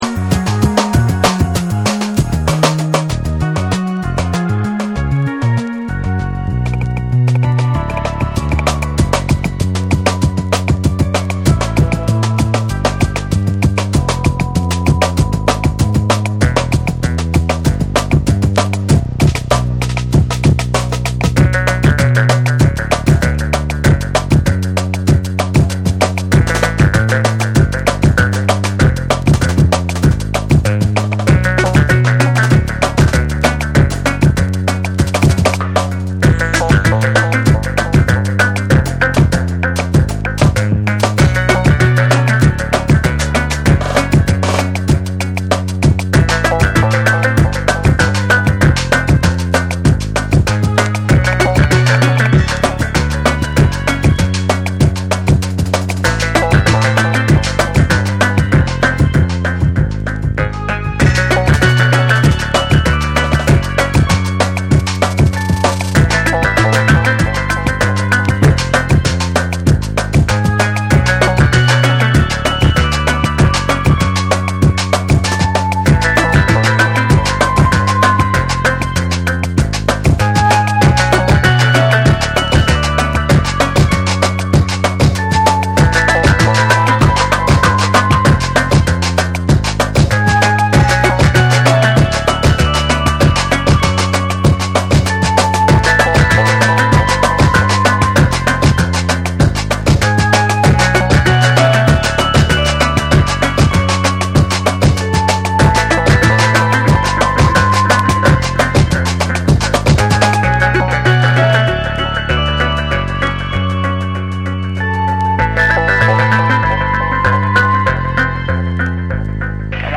複雑に組み上げられた緻密なビートと、温かみあるメロディが共存する
流麗かつ幻想的なサウンドスケープを描く
BREAKBEATS